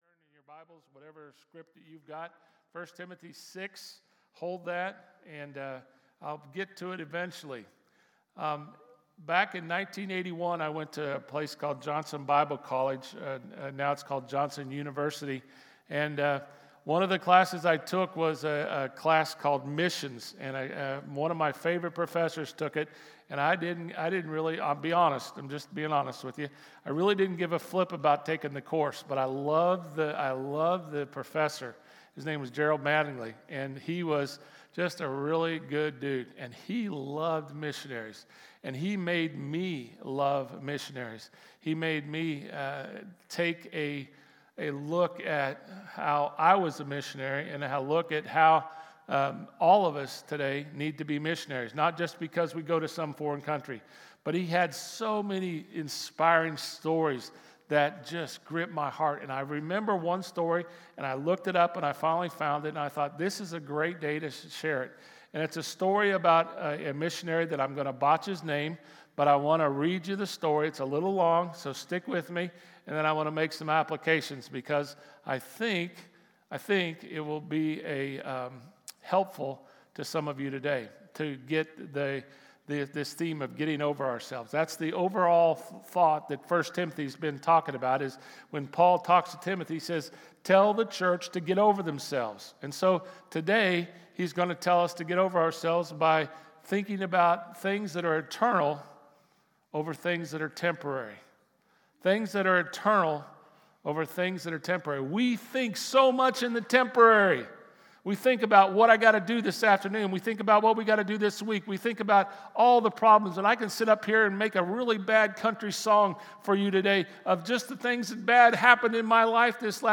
Sermon-5-11-25.mp3